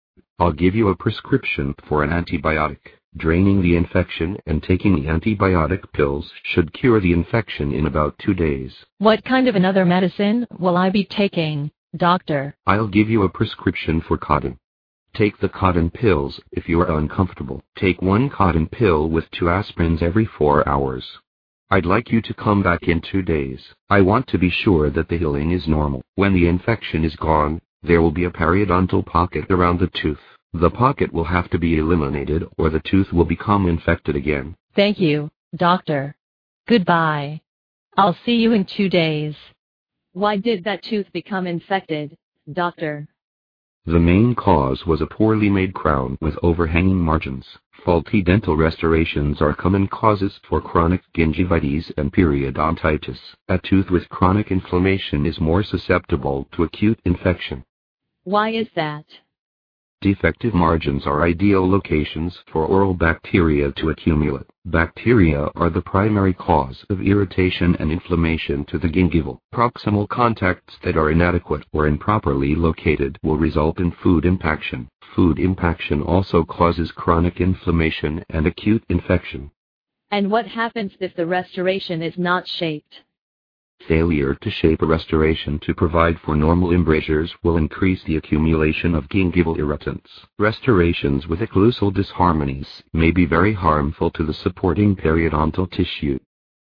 收录了口腔医学医患、医助之间的对话，非常适合医学生、临床医务人员练习专业口语和听力，在欧洲很受欢迎。